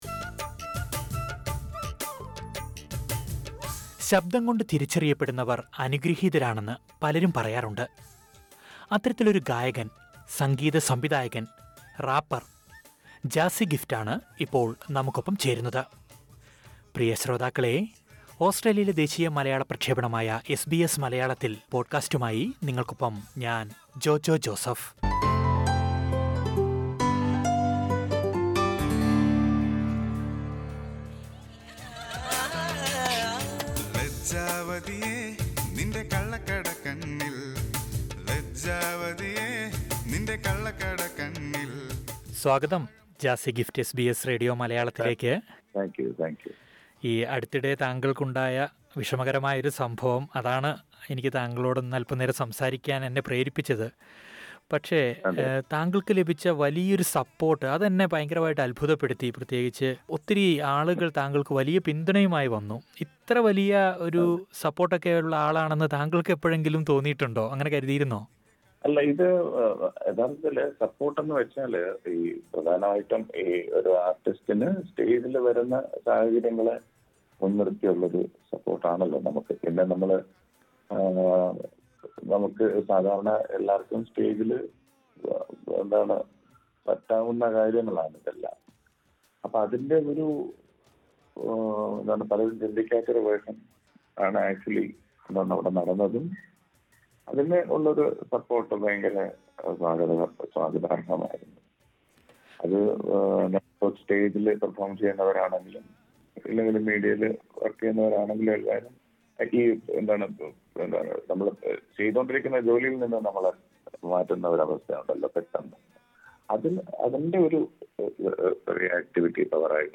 കോളേജിലെ പരിപാടിക്കിടെ പ്രിന്‍സിപ്പാല്‍ സ്റ്റേജില്‍ നിന്ന് ഇറക്കിവിട്ടതിനു പിന്നാലെ, പ്രശസ്ത ഗായകനും സംഗീത സംവിധായകനുമായ ജാസി ഗിഫ്റ്റിന് പിന്തുണയുമായി ഒട്ടേറെ പേരാണ് രംഗത്തെത്തിയത്. ഈ പിന്തുണ എത്രത്തോളം സഹായകമായി എന്നും, പാട്ടിന്റെ വഴിയിലെ യാത്രയെക്കുറിച്ചുമെല്ലാം ജാസി ഗിഫ്റ്റ് എസ് ബി എസ് മലയാളത്തോട് സംസാരിക്കുന്നത് കേള്‍ക്കാം...